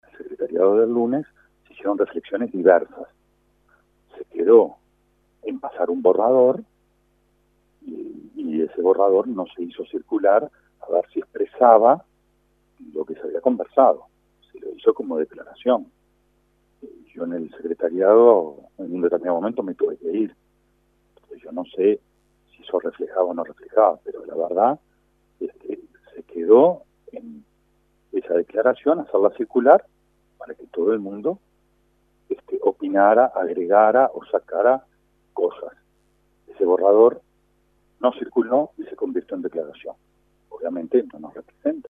El senador e integrante del Frente Líber Seregni, Rafael Michelini, dijo a 810 Vivo que la declaración que se emitió era un borrador que debía ponerse a consulta de los distintos sectores del partido.